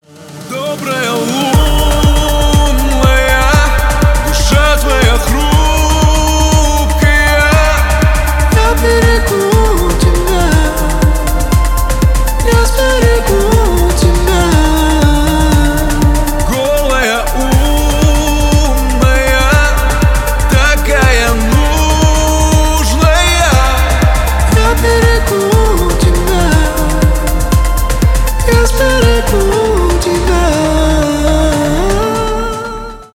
клубные , поп , русские
afro house , танцевальные